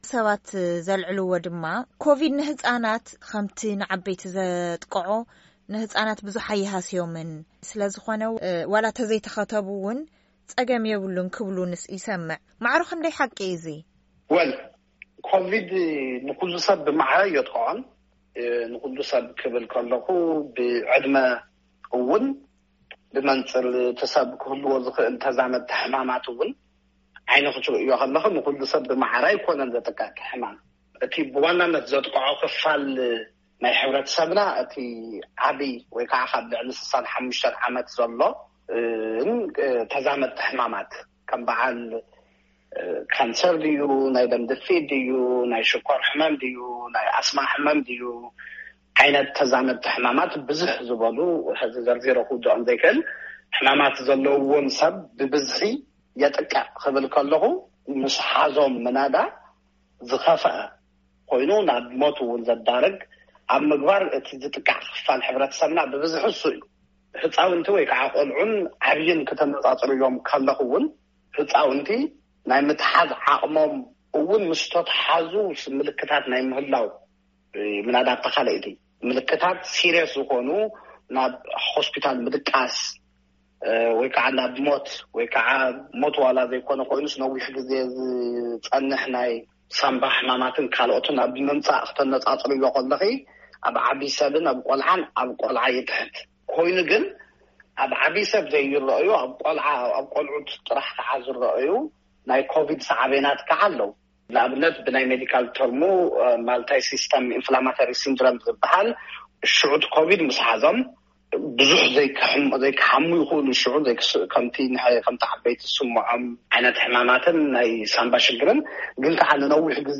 ቃለ መሕትት ኣብ ጉዳይ ክታበት ቫይረስኮሮና ንህጻናት